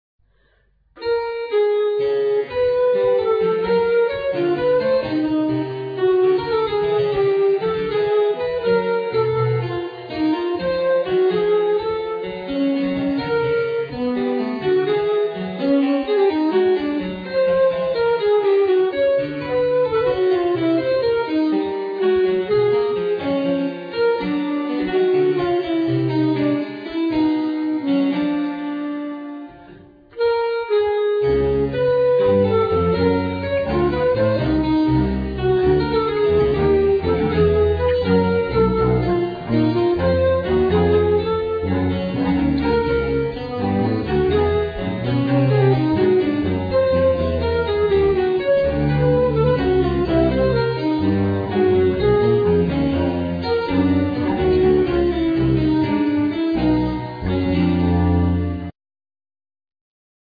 Compositions,Tuba,Saqueboute,Trombone,Voice
Flute,Traverso,Voice
Harpsichord
Viola da gamba
Percussions